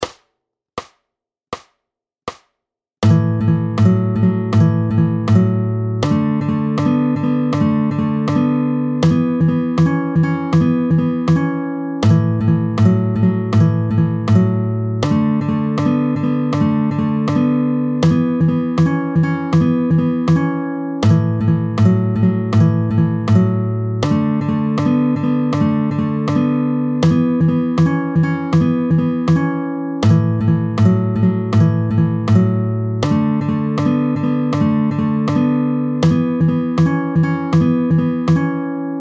32-14 Le honky tonk 4, barrés complets, tempo 80
À la main droite, on ne joue toujours que sur 2 ou 3 cordes.